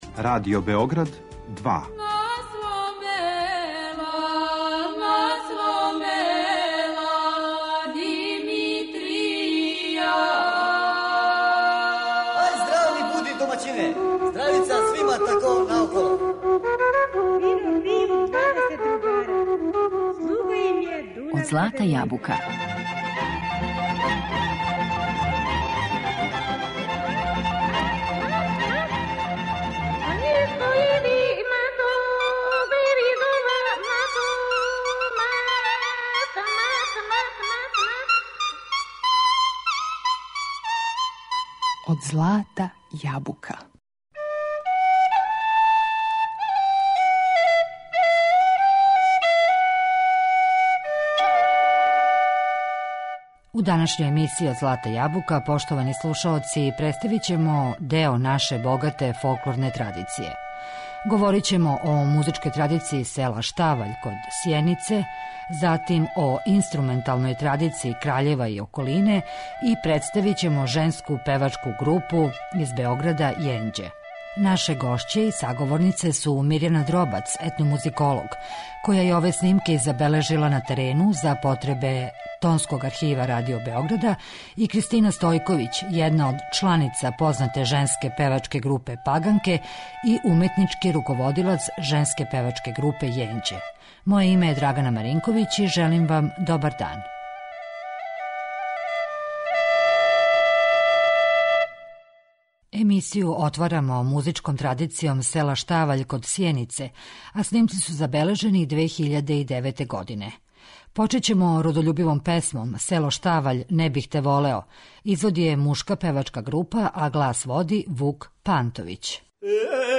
Говорићемо о музичкој традицији села Штаваљ код Сјенице, о инструменталној традицији Краљева и околине и представићемо женску певачку групу из Београда "Јенђе".